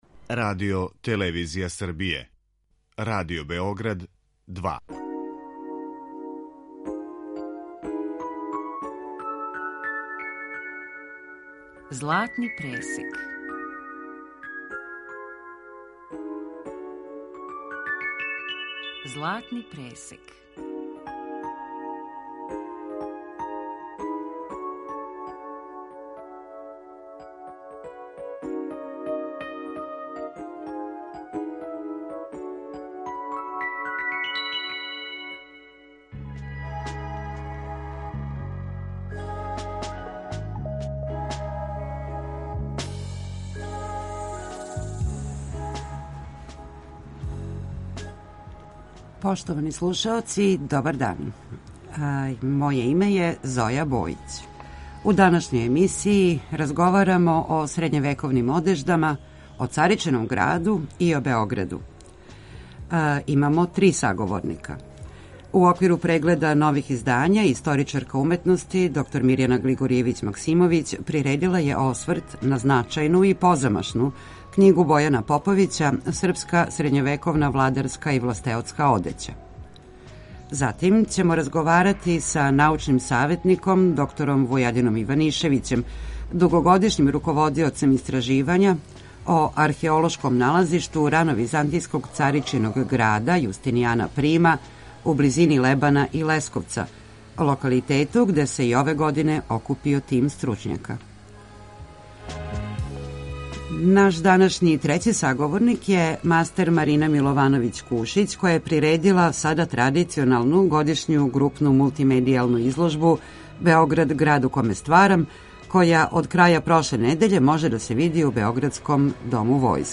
У данашњој емисији разговарамо са три саговорника.